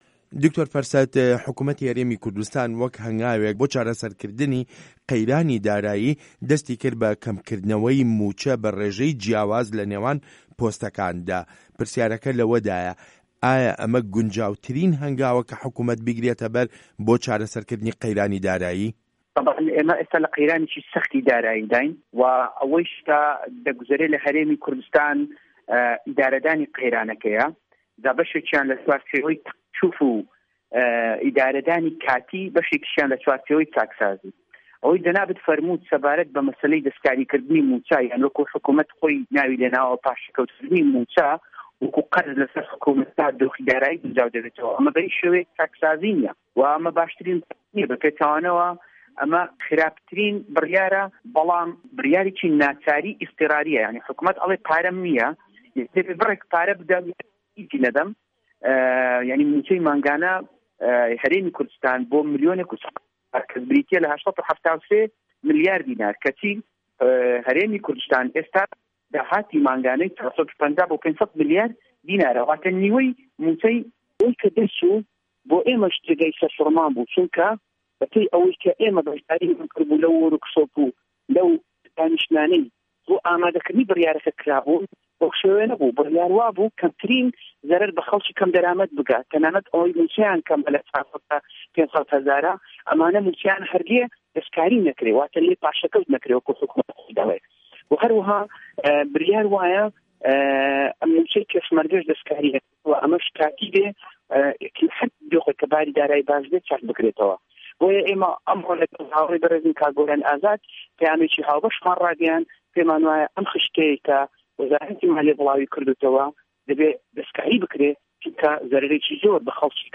وتووێژ لەگەڵ دکتۆر فەرسەت سۆفی